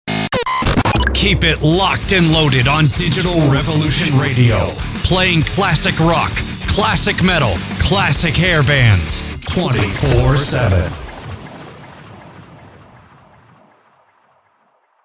The station ID, sometimes called a legal ID, is a short identifier that includes the call letters, frequency, and city of license.